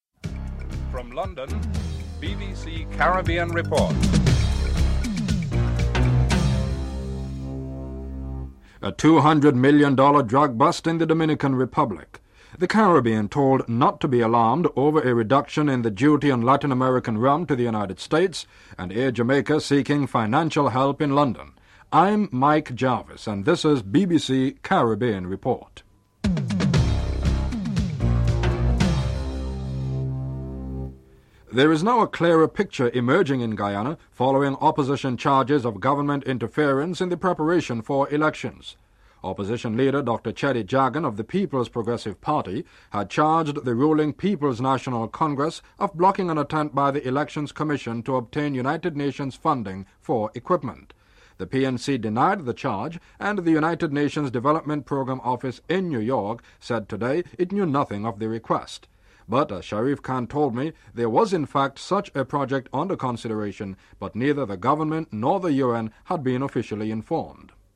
1. Headlines (00:00-00:30)
Republican Congressman, Phil Crane, states that the fears of the Caribbean producers are unfounded (07:45-11:25)